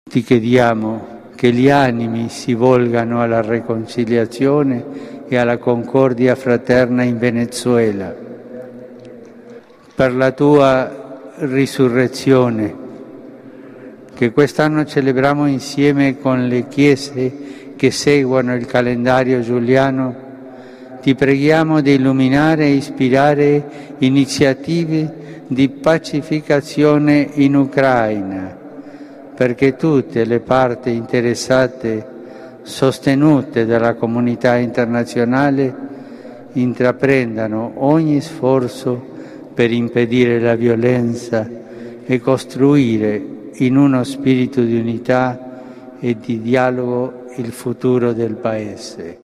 Francisco ha impuesto la bendiciónurbi et orbi con motivo del Domingo de Resurrección.
Lo ha hecho ante más de 150.000 fieles congregados frente a la basílica vaticana.